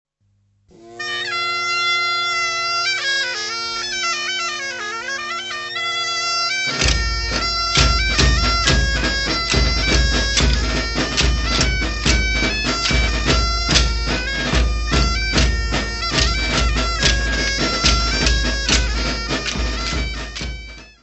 Folclore português : Trás-os-Montes e Alto Douro
Grupo Folclórico Mirandês de Duas Igrejas